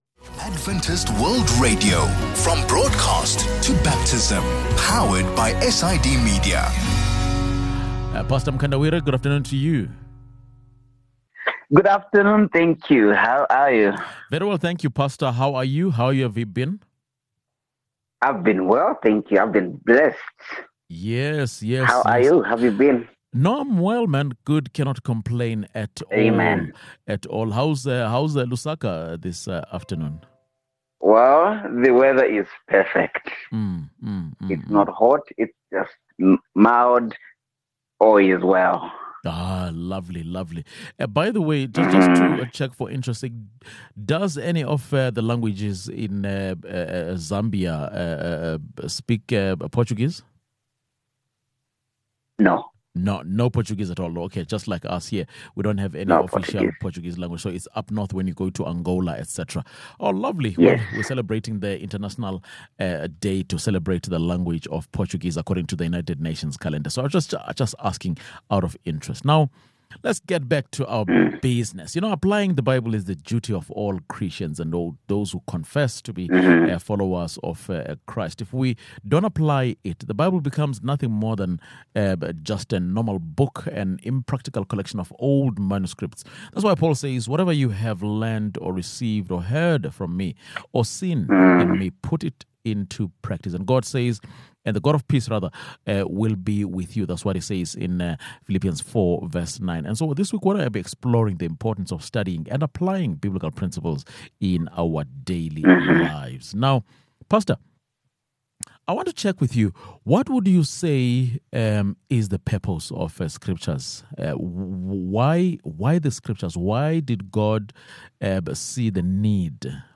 Applying the Bible is the duty of all Christians. In this conversation, we will be exploring the importance of studying and applying Biblical principles in our daily lives.